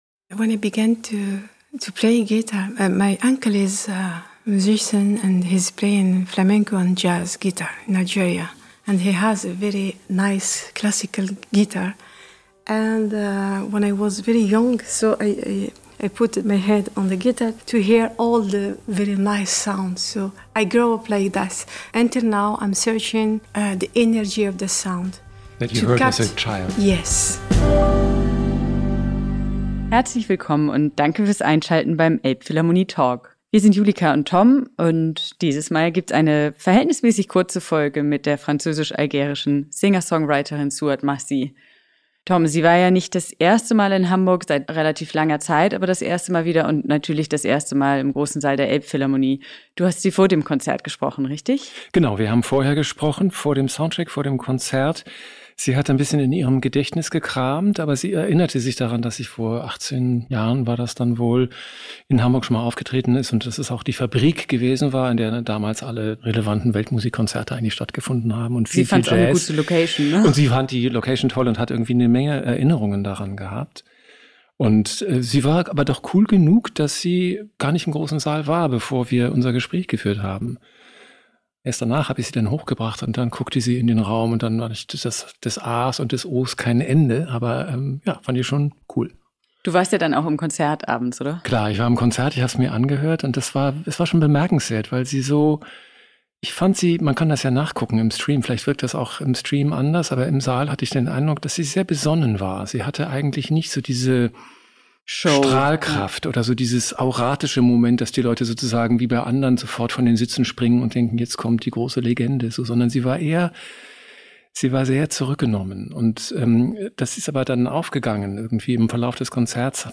Über Heimat und Heavy Rock – im Gespräch mit Souad Massi